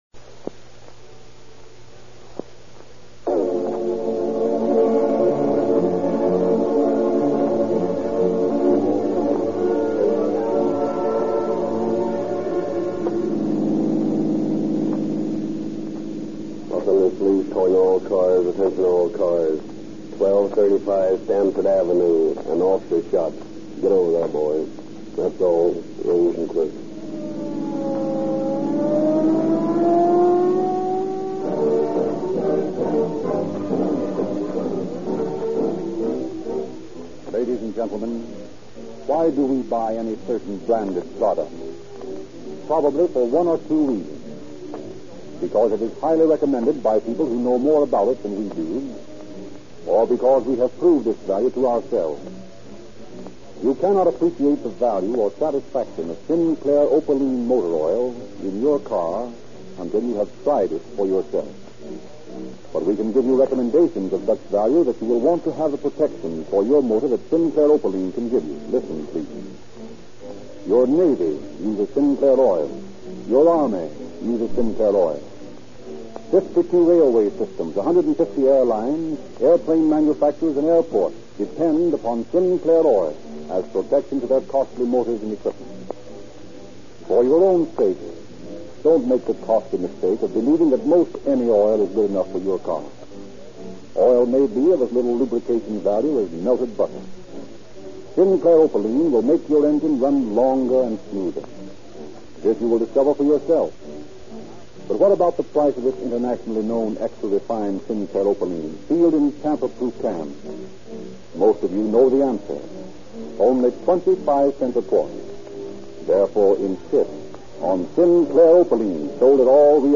Calling All Cars Radio Program